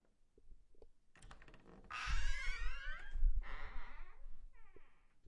门吱吱作响
描述：门吱吱作响打开
Tag: 咯吱